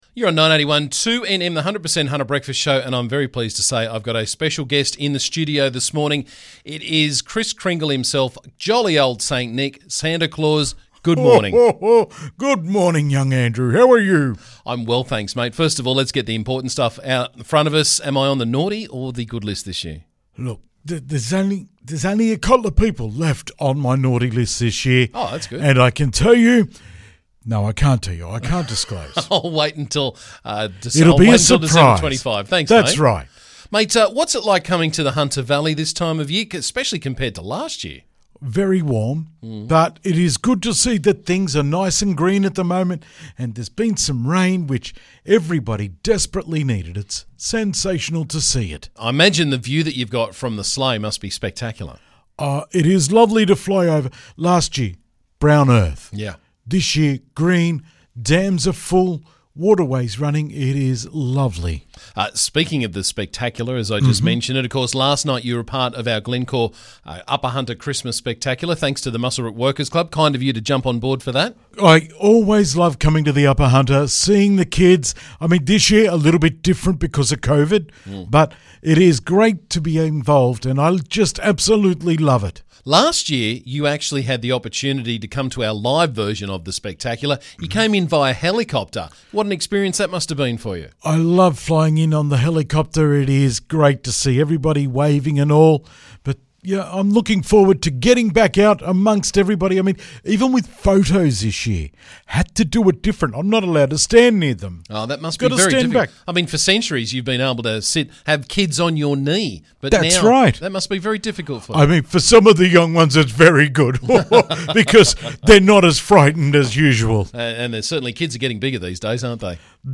So I asked him to join me for a chat on the show this morning.